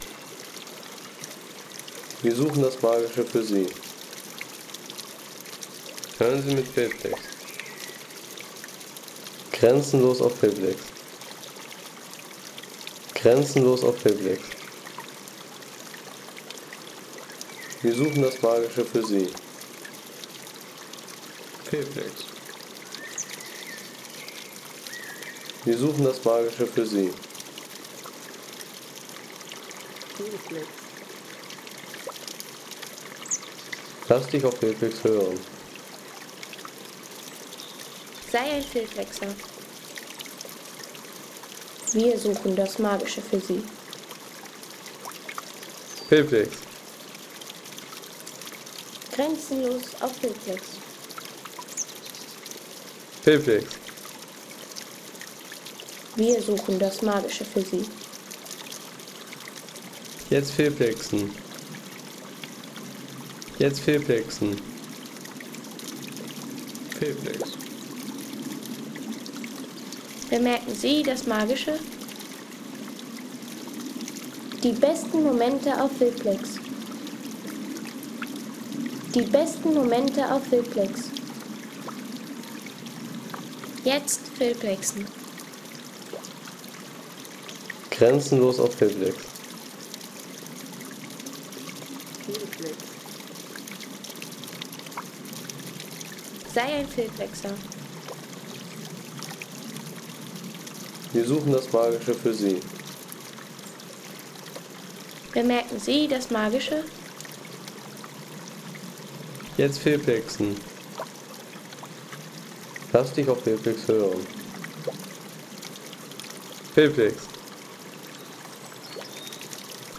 Landschaft - Bäche/Seen
Kleiner Wasserbach an der Teufelsbrücke – Ruhe und Natur im Bergpar ... 3,50 € Inkl. 19% MwSt.